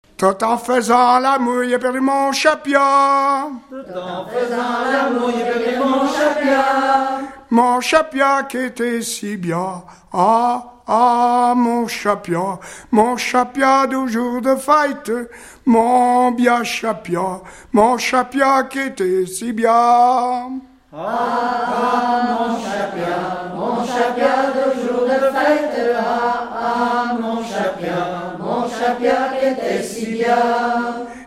Saint-Etienne-du-Bois
Genre énumérative
Pièce musicale inédite